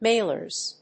/ˈmelɝz(米国英語), ˈmeɪlɜ:z(英国英語)/